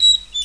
00425_Sound_WasserHahnZu.mp3